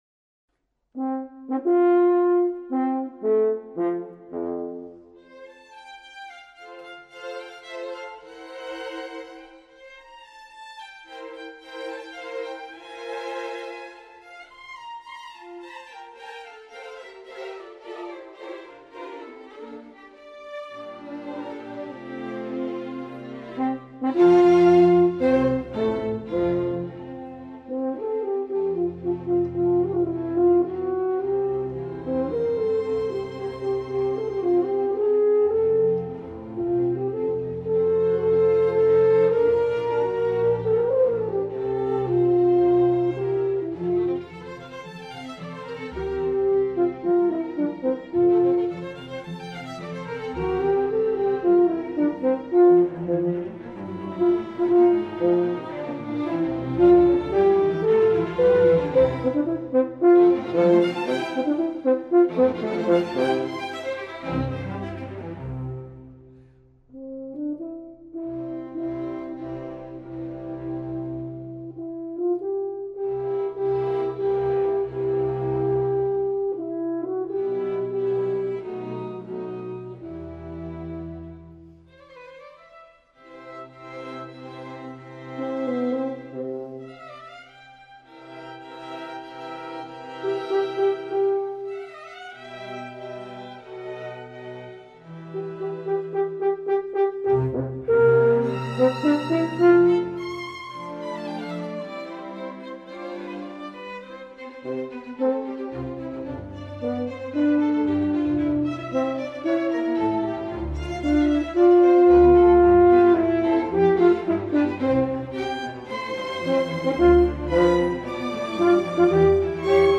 per corno e archi